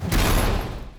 rocket sounds
fire2.wav